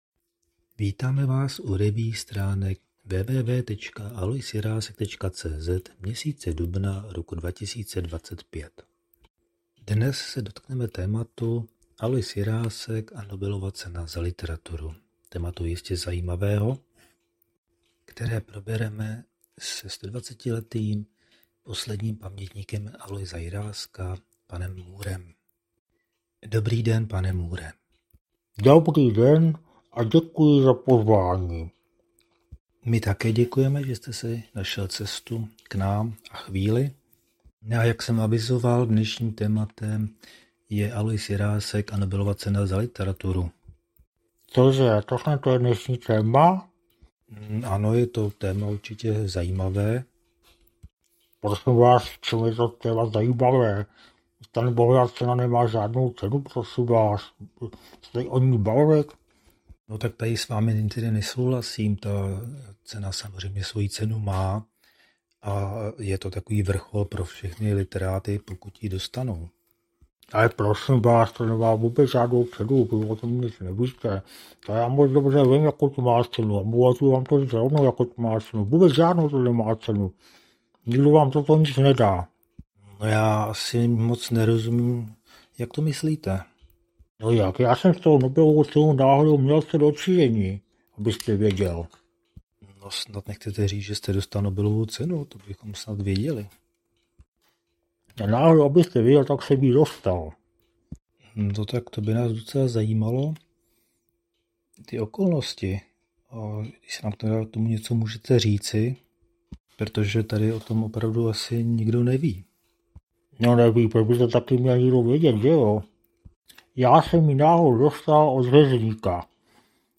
Další pokus o kombinovanou revue s obvyklou humornou nadsázkou zjišťuje, jak to bylo s Nobelovou cenou za literaturu pro Aloise Jiráska.